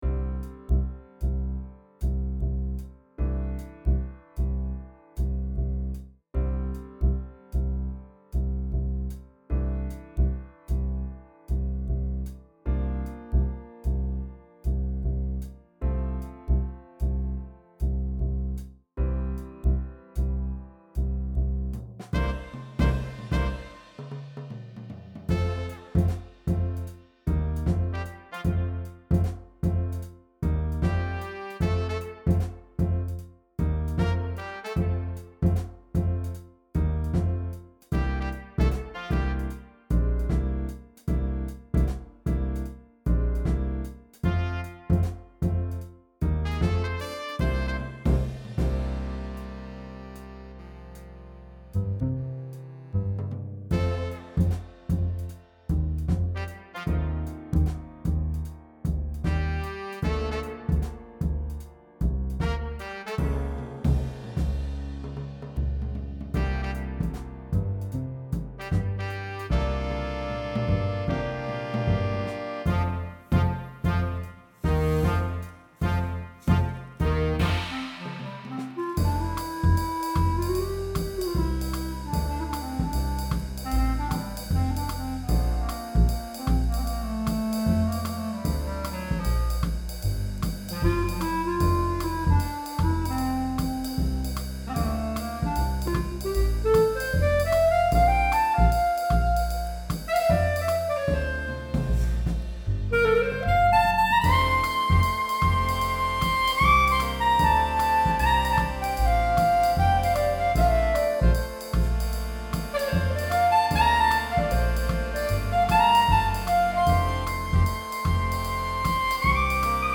Style: 2nd Line Swing